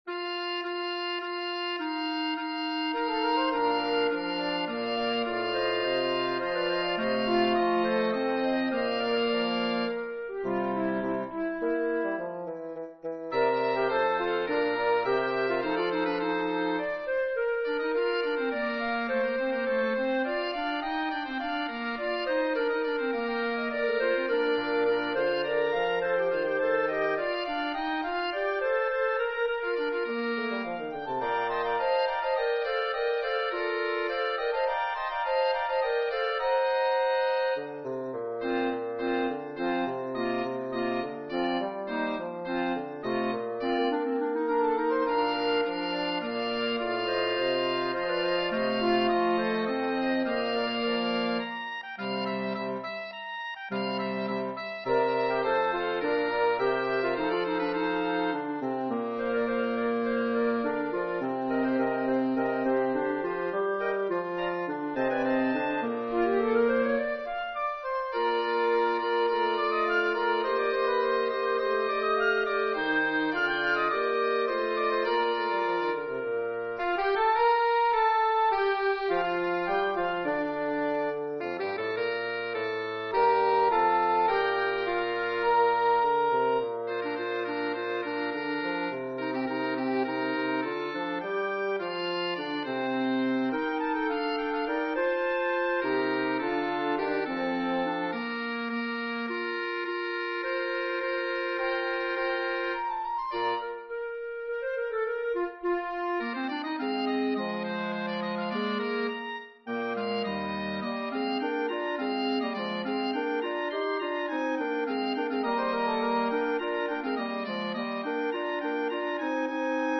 Bb, Eb
woodwind quintet
fantasia